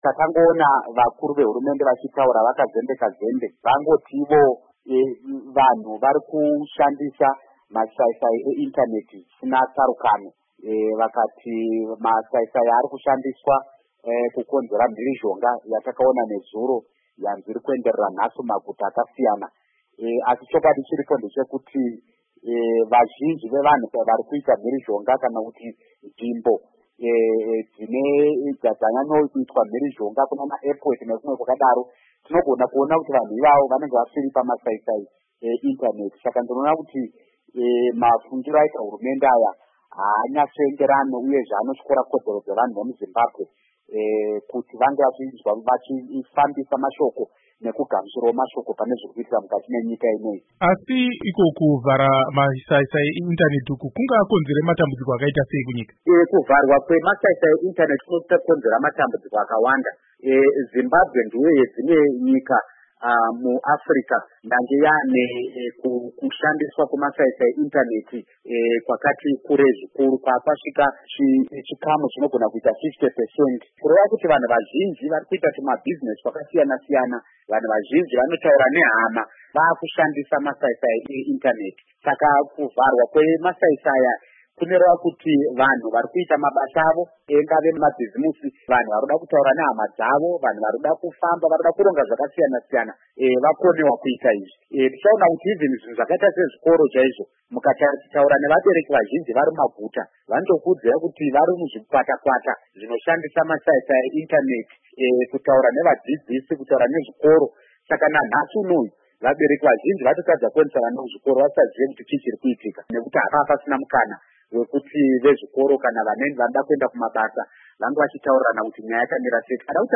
Hurukuron